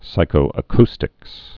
(sīkō-ə-kstĭks)